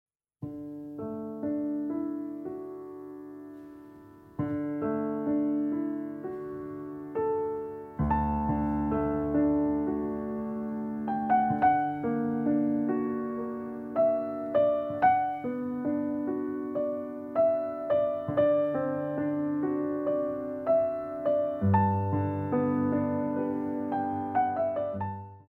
Jambe  à la barre ou pieds  à la main ou adage